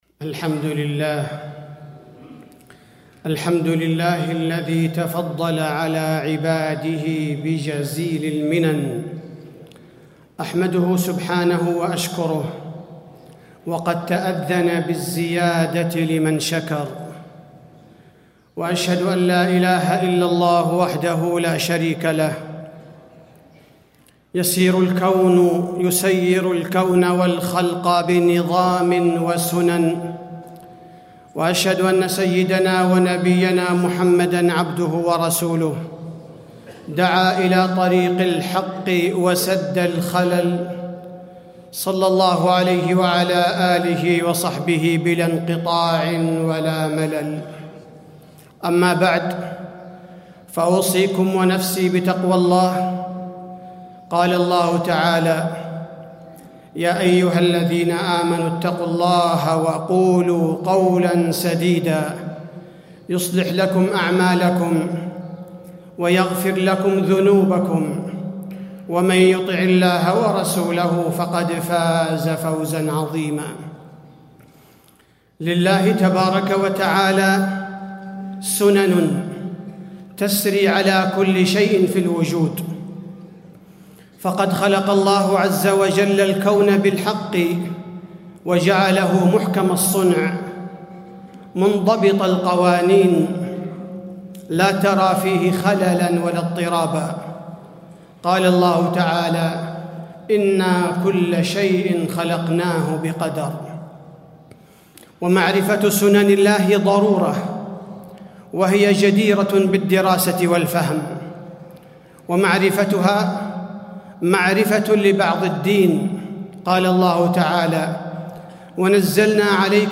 تاريخ النشر ٩ ربيع الأول ١٤٣٥ هـ المكان: المسجد النبوي الشيخ: فضيلة الشيخ عبدالباري الثبيتي فضيلة الشيخ عبدالباري الثبيتي دراسة السنن الكونية عبرة وعظة